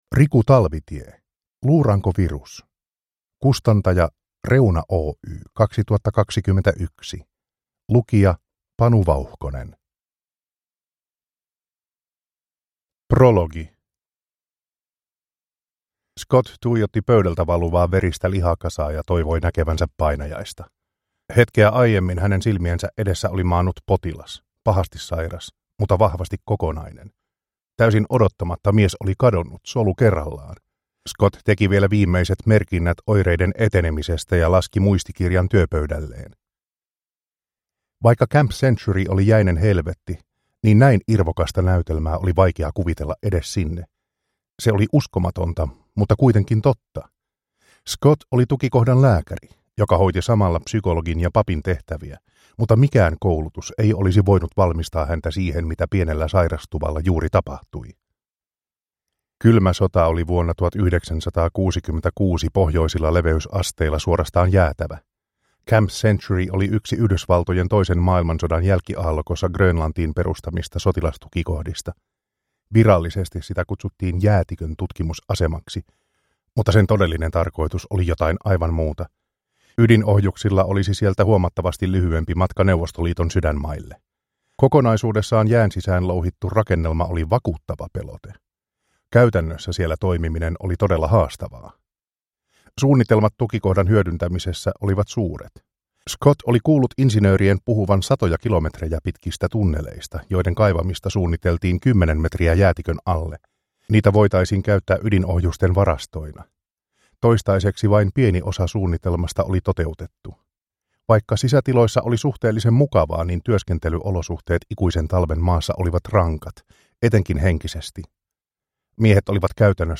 Luurankovirus – Ljudbok – Laddas ner